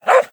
Minecraft Version Minecraft Version 1.21.5 Latest Release | Latest Snapshot 1.21.5 / assets / minecraft / sounds / mob / wolf / puglin / bark2.ogg Compare With Compare With Latest Release | Latest Snapshot
bark2.ogg